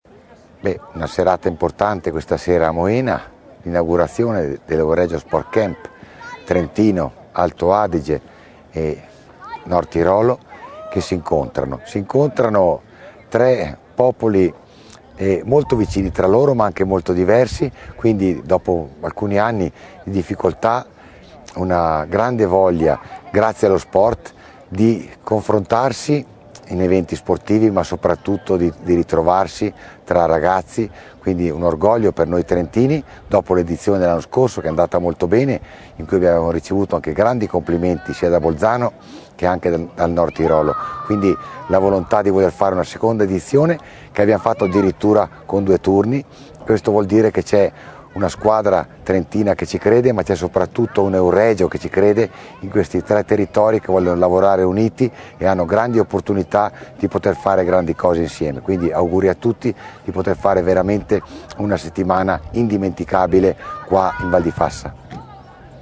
INTV_ROBERTO_FAILONI_IT_(1).mp3